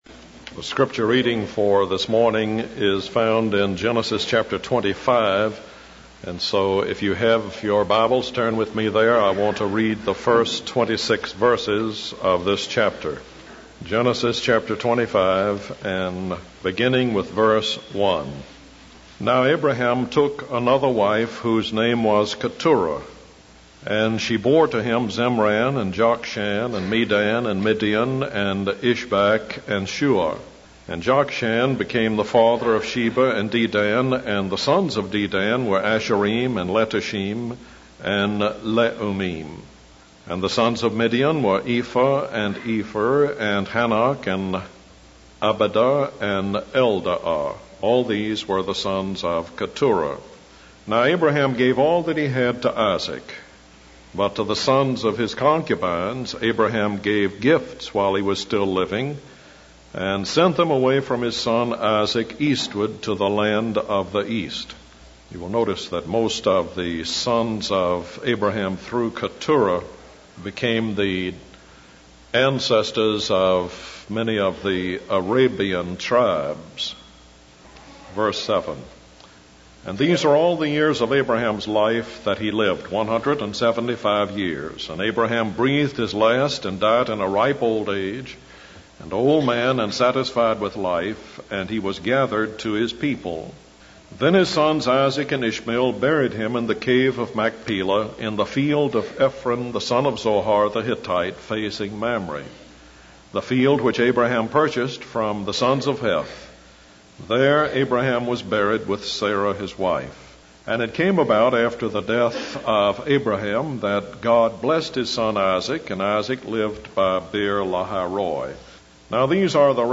In this sermon, the speaker begins by reading from the book of Hosea, specifically verses 2-4. The passage talks about God's dispute with Judah and his punishment of Jacob according to his ways. The speaker then transitions to discussing the grace of God and the importance of recognizing one's sin and receiving Jesus as a personal savior.